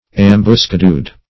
Ambuscadoed \Am`bus*ca"doed\
ambuscadoed.mp3